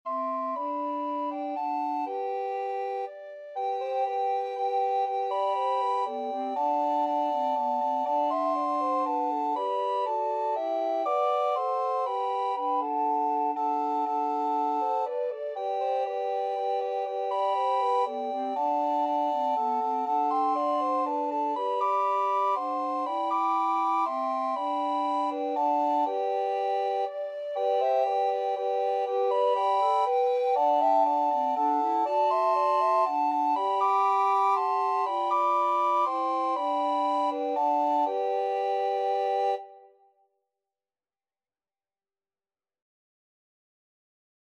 Soprano RecorderAlto RecorderTenor RecorderBass Recorder
G major (Sounding Pitch) (View more G major Music for Recorder Quartet )
3/4 (View more 3/4 Music)
Lively ( = c.120)
Recorder Quartet  (View more Easy Recorder Quartet Music)
Traditional (View more Traditional Recorder Quartet Music)